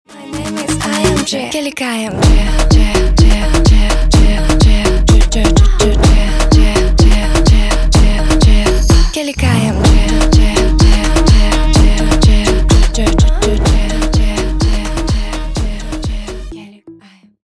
на русском клубные